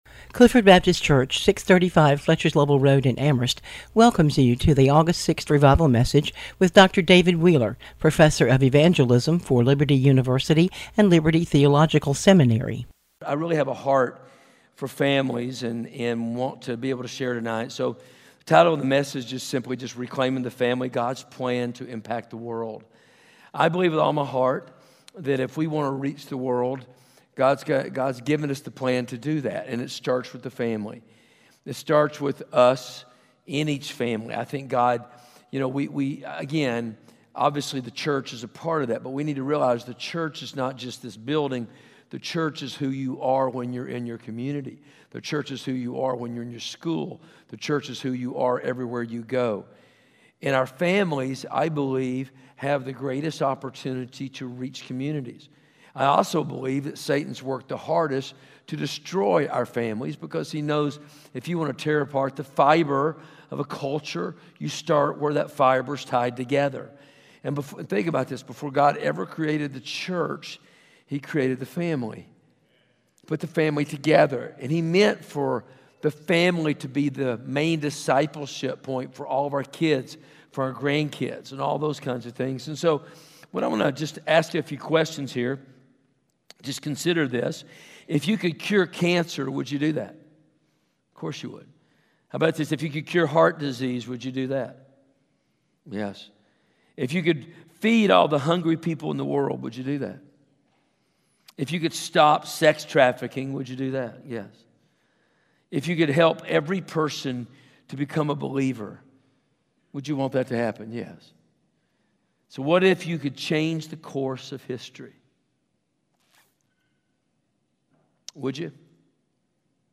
Clifford Baptist Revival Service